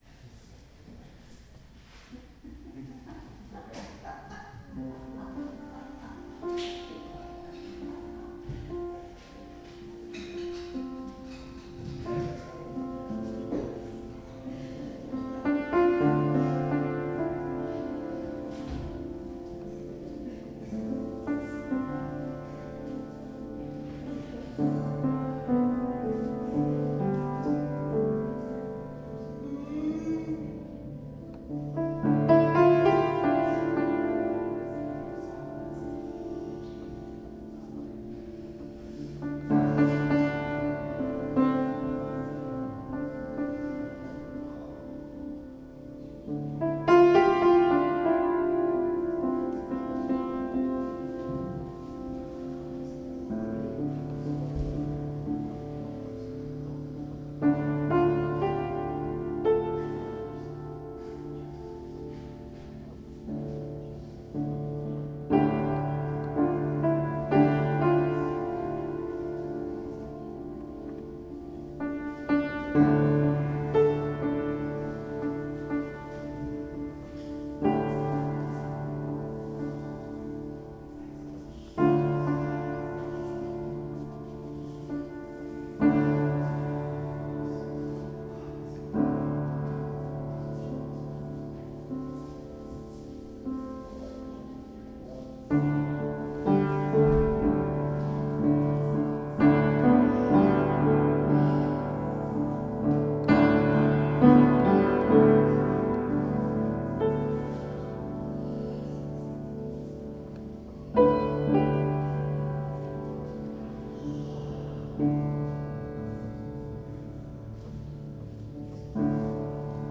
improv jam
flute
Flute and Piano [ 9:32 ] Play Now | Play in Popup | Download